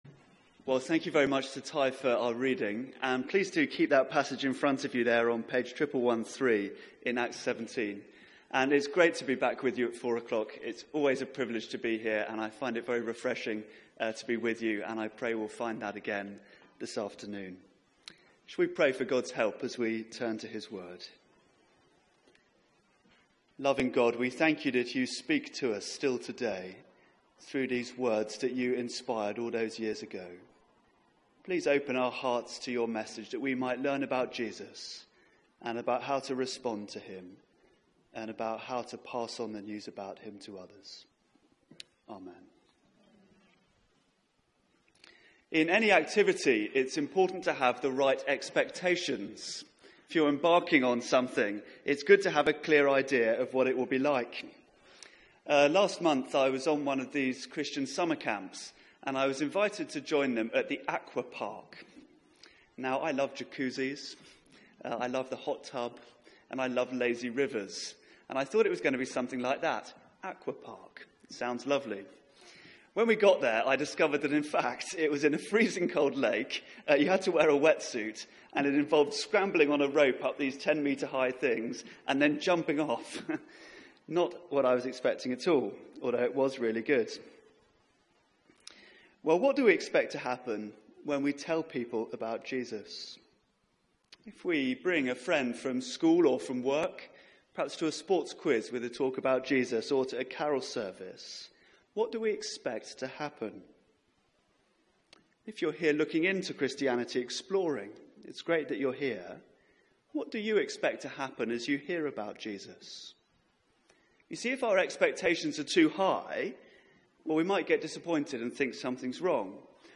Media for 4pm Service on Sun 20th Aug 2017 16:00 Speaker
Series: Travels with Paul Theme: A gospel that divides Sermon Search the media library There are recordings here going back several years.